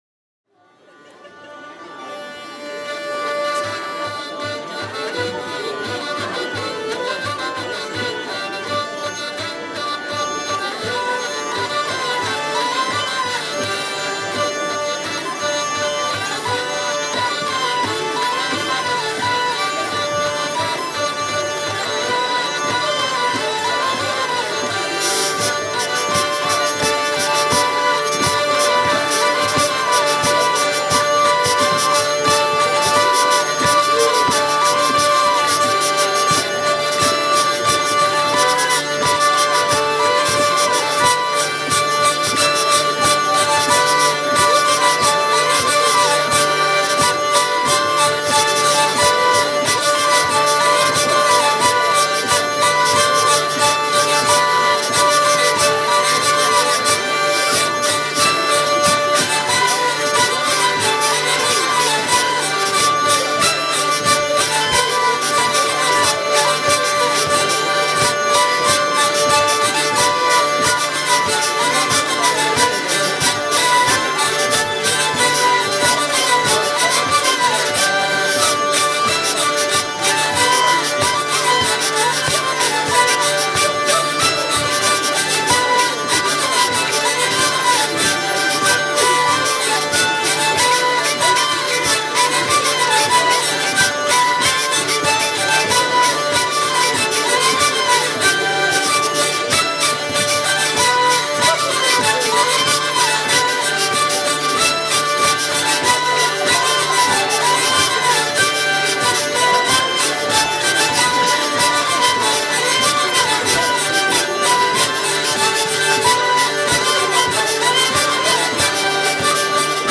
:mp3:2013:soiree_stagiaires
19_scottish-vielles.mp3